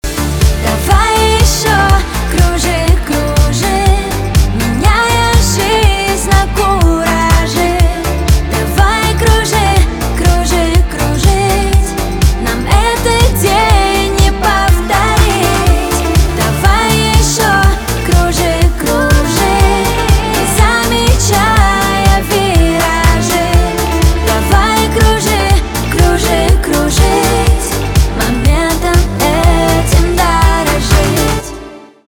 поп
романтические , битовые